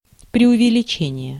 Ääntäminen
IPA : /haɪˈpɝːbəli/